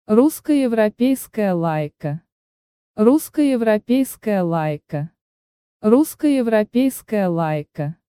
Say it in Russian: